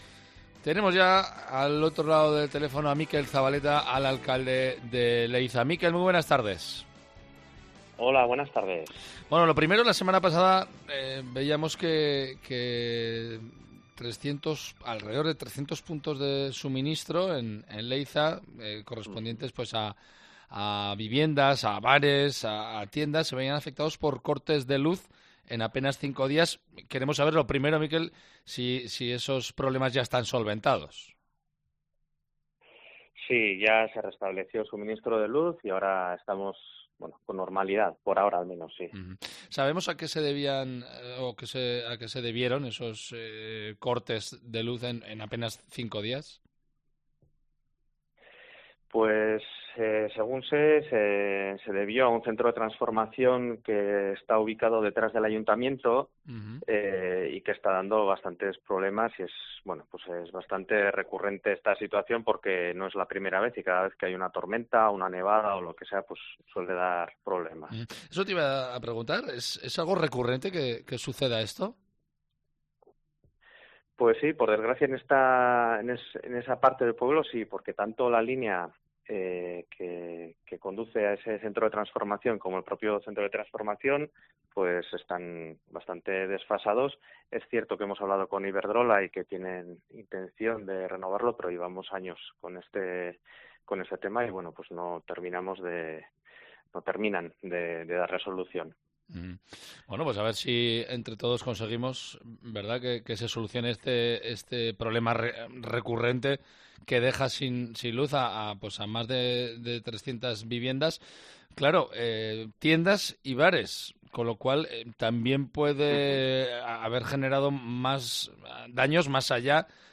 El alcalde de Leitza Mikel Zabaleta relata los cortes de luz que vive la localidad de forma recurrente y que ha causado varios perjuicios.